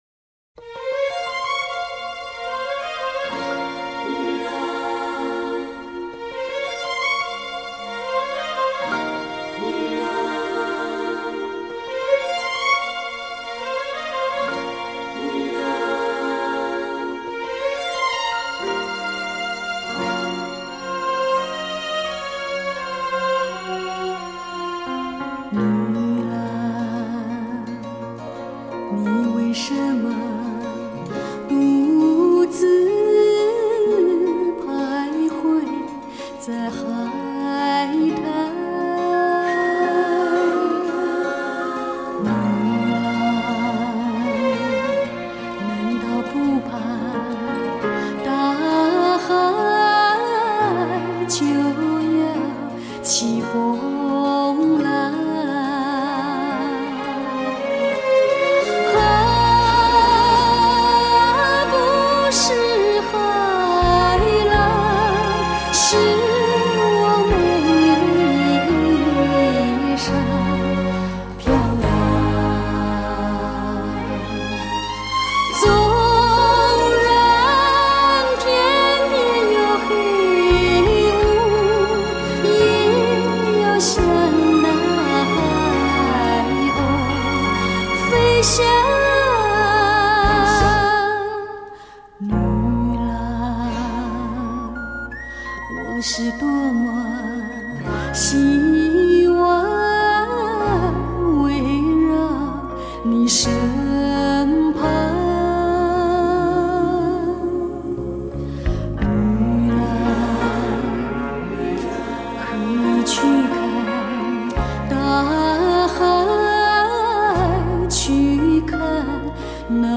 轻柔的声音在空中飘扬
令人感到心旷神怡